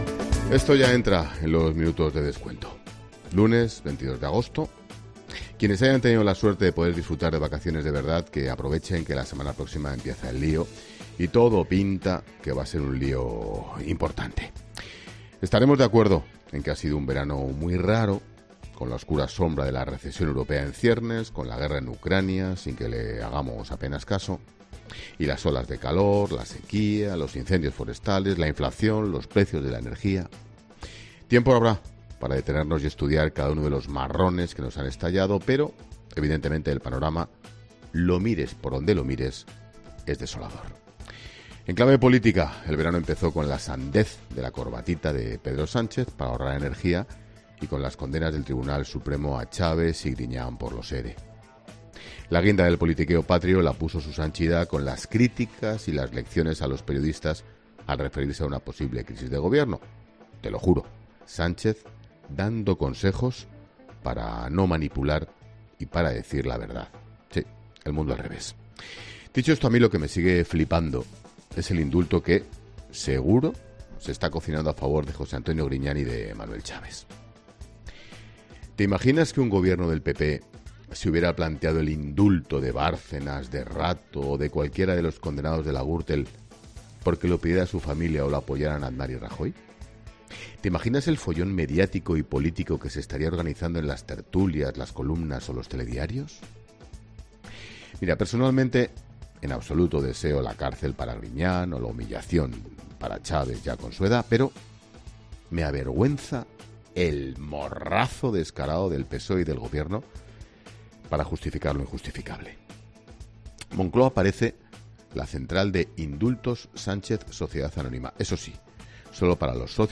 Monólogo de Expósito
El director de 'La Linterna', Ángel Expósito realiza un repaso sobre la actualidad política y analiza la situación ante la que se encuentran José Antonio Griñán y Manuel Chaves